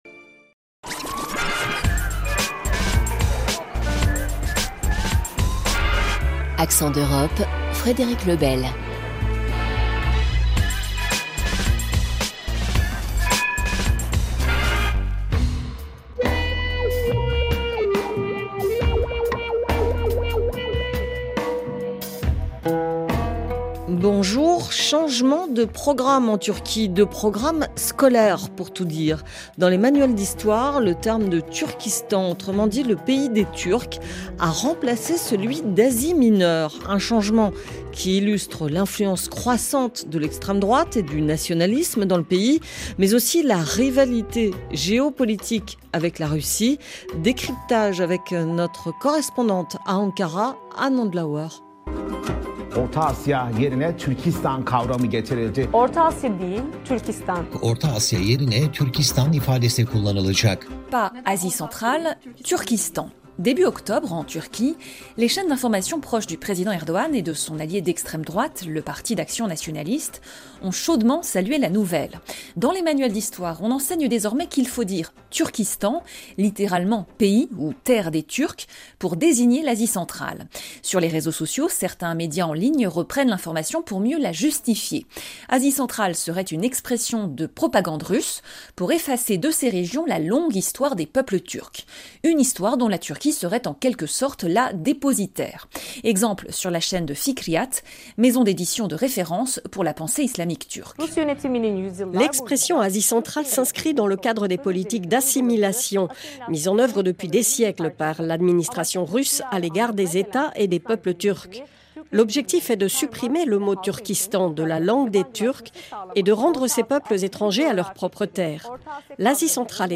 Pinar Selek que nous avons rencontrée dans le cadre du festival Un week-end à l’Est qui se tient jusqu’au 30 novembre 2024 à Paris.